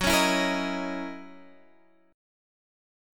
F#13 chord